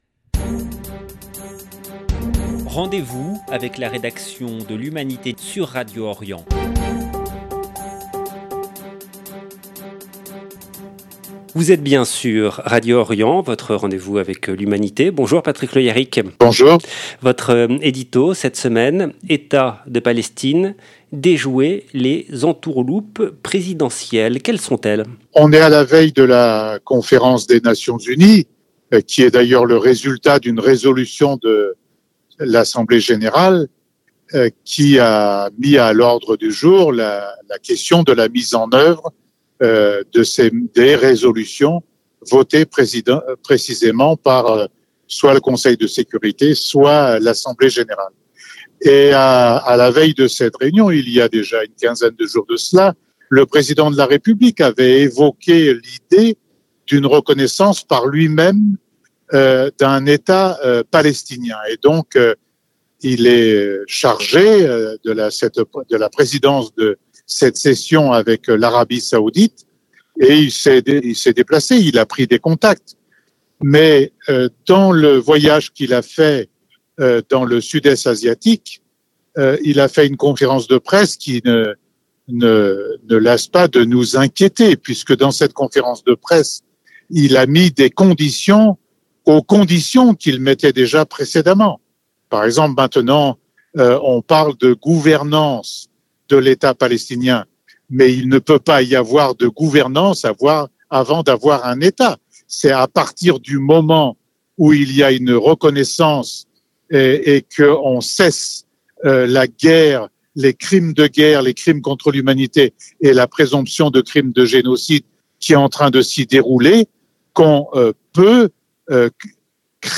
Comme chaque semaine, nous avons rendez-vous avec Patrick Le Hyaric, éditorialiste à l’Humanité. Son édito cette semaine a pour titre “ État de Palestine : déjouer les entourloupes présidentielles” Dans cet édito, il évoque la reconnaissance de l’Etat palestinien annoncée « sous condition » par Emmanuel Macron. Il alerte sur le risque d’un geste symbolique sans effet réel, et appelle à des mesures concrètes pour garantir les droits du peuple palestinien. 0:00 5 min 29 sec